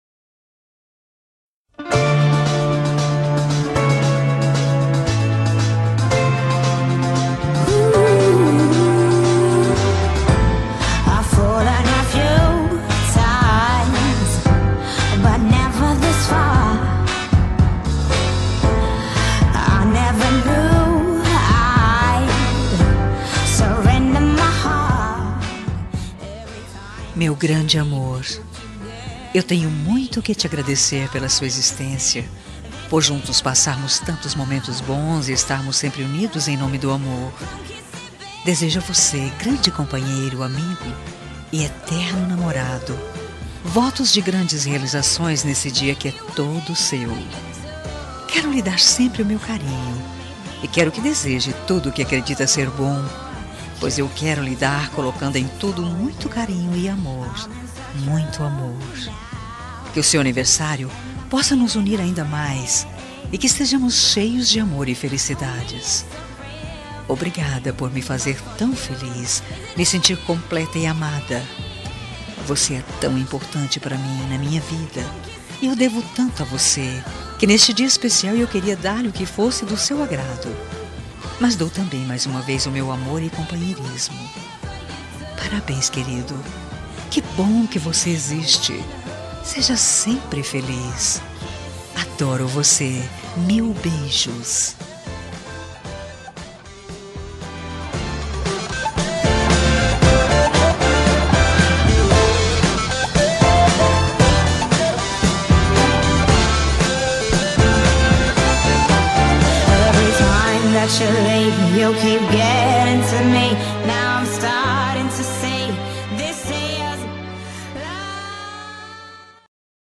Telemensagem de Aniversário de Marido – Voz Feminina – Cód: 9092